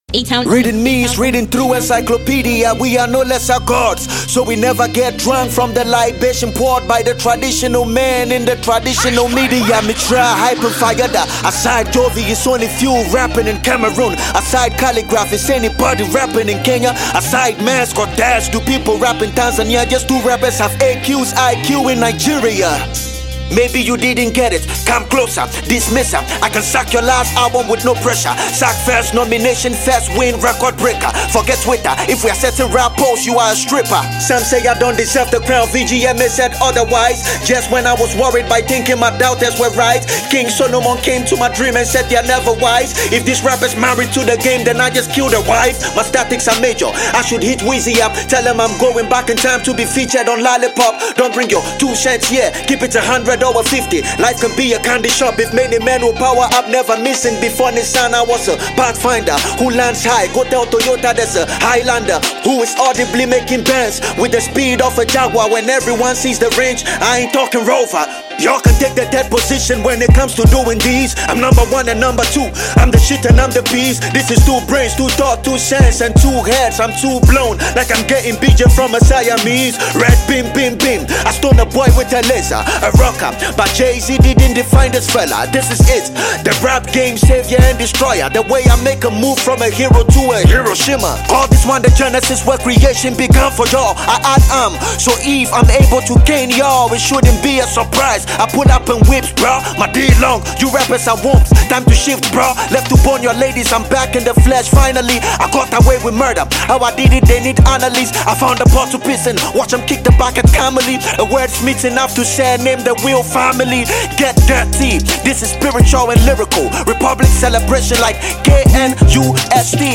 Ghanaian rapper
annual rap song